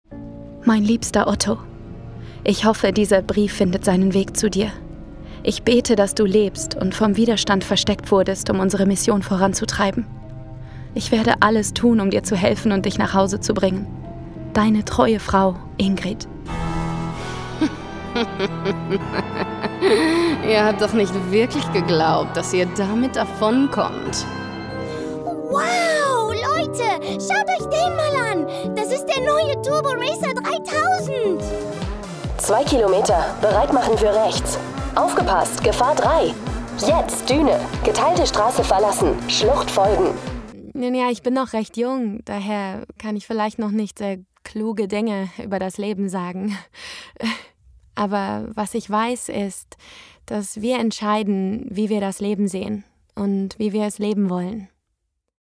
Natural, Cool, Travieso, Versátil, Amable